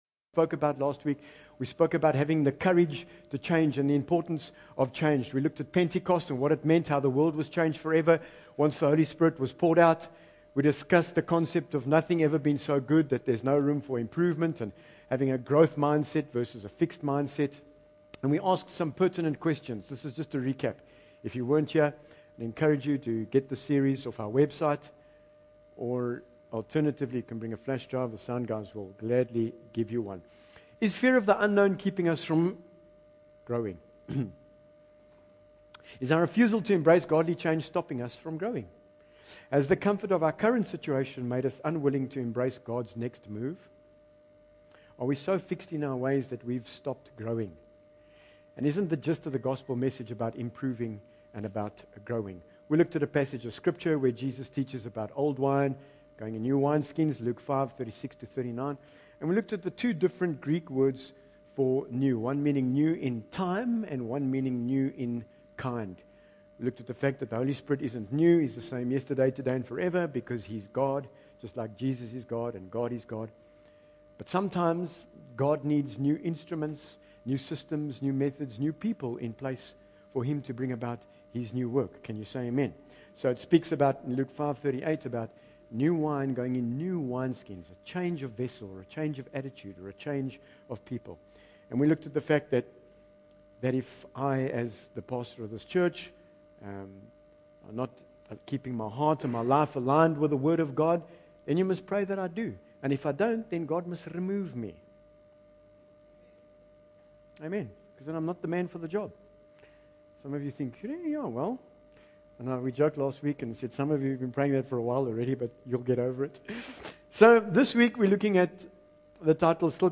Bible Text: 1 Chronicles 28 : 20 | Preacher